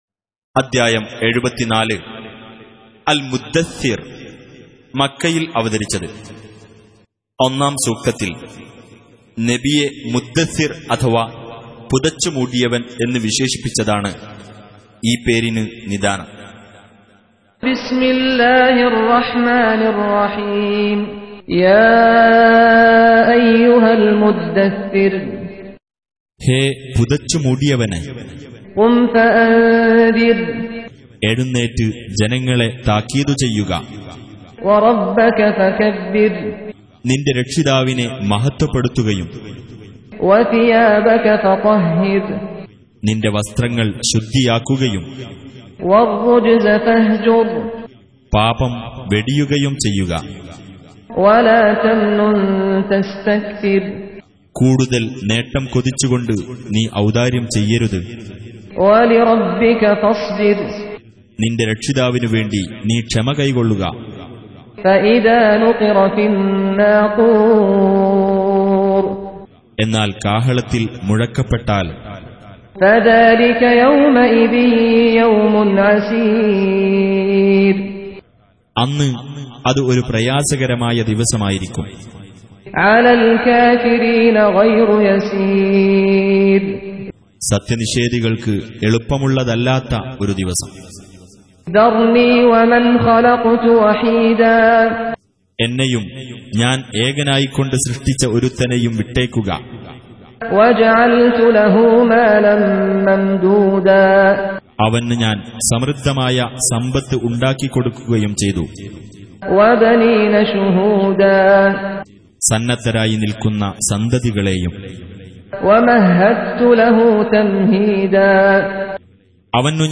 Surah Repeating تكرار السورة Download Surah حمّل السورة Reciting Mutarjamah Translation Audio for 74. Surah Al-Muddaththir سورة المدّثر N.B *Surah Includes Al-Basmalah Reciters Sequents تتابع التلاوات Reciters Repeats تكرار التلاوات